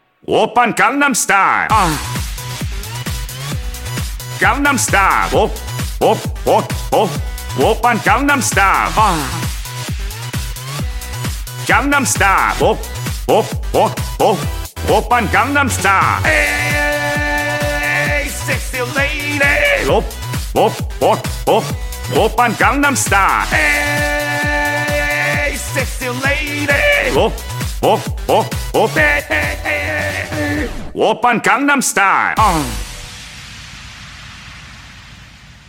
[~] Applying audio effects to Vocals...
[~] Combining AI Vocals and Instrumentals...